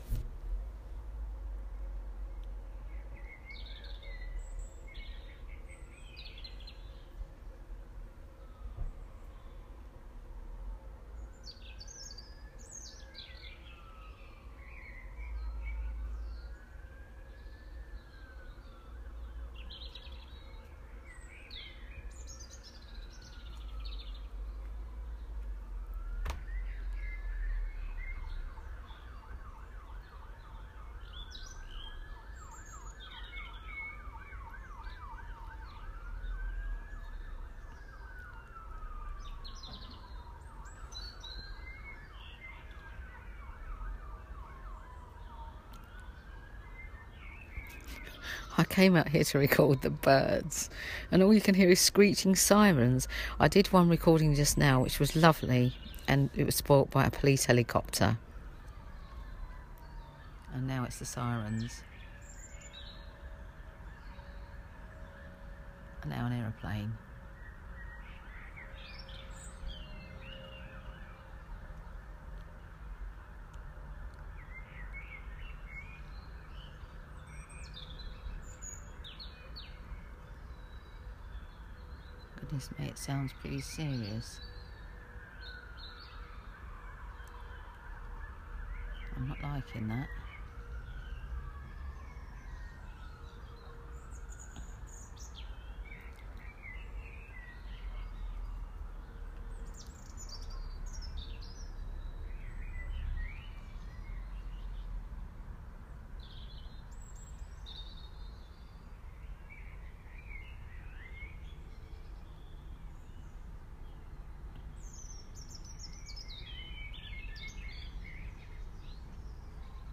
Evensong and sirens, Wednesday 15 March 2017